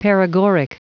Prononciation du mot paregoric en anglais (fichier audio)
Prononciation du mot : paregoric